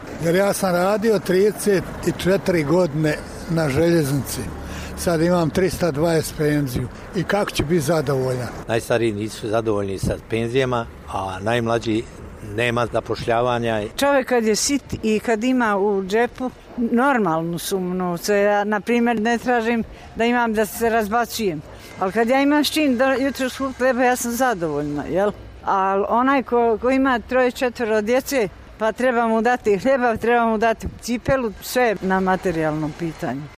Glavni razlog njihovog nezadovoljstva, kako kažu Dobojlije, je neimaština u kojoj žive: